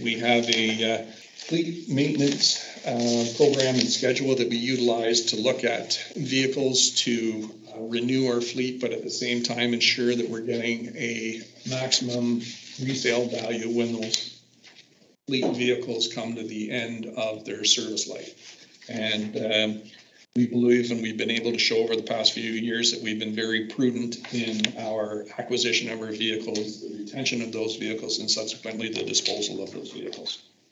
Callaghan spoke about the process they take with police vehicles.